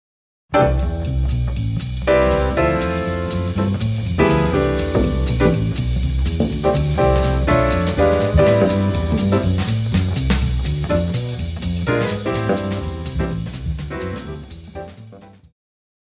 Jazz
Band
Instrumental
Blues
Only backing